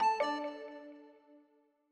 Longhorn 2000 - Notify.wav